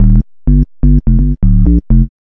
3508L B-LOOP.wav